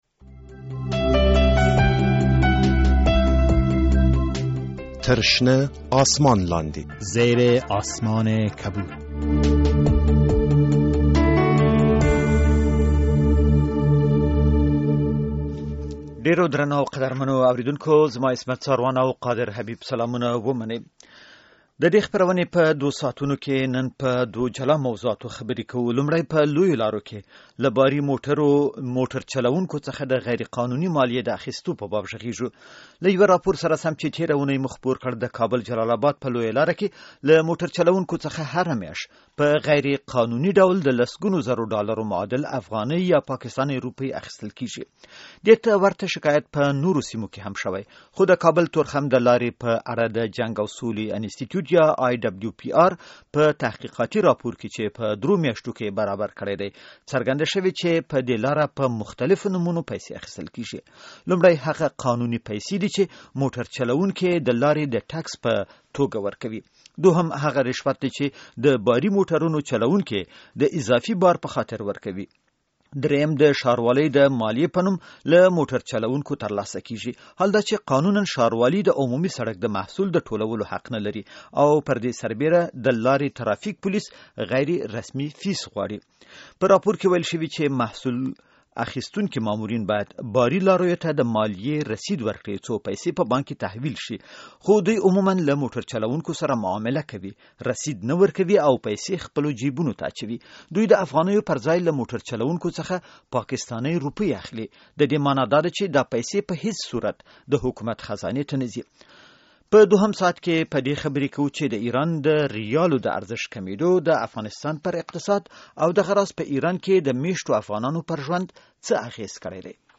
در برنامهء این هفته زیر آسمان کبود که به روز شنبه به طور مستقیم از طریق رادیو آزادی نشر شد، بحث روی دو موضوع متمرکز بود. در بخش نخست که از ساعت 3:00 الی 4:00 بعد از ظهر ادامه یافت، روی اخاذی ها از موتر های باربری بحث صورت گرفت.